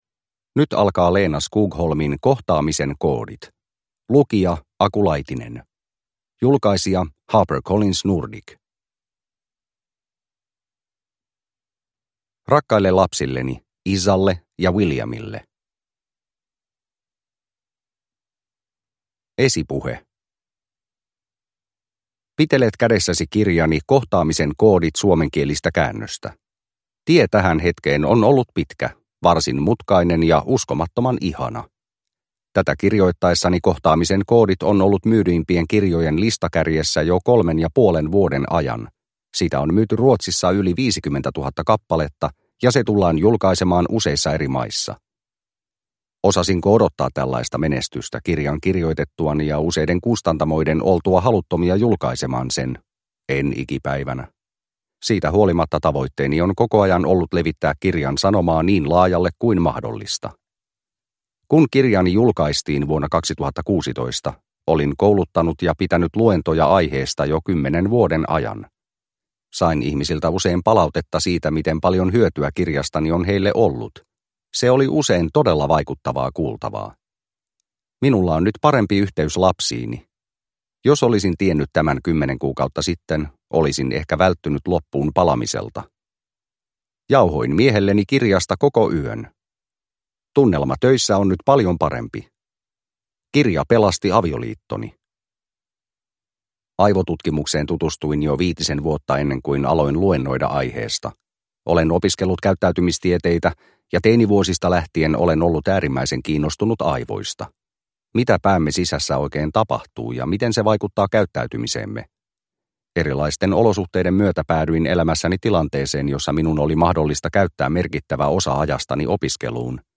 Kohtaamisen koodit – Ljudbok – Laddas ner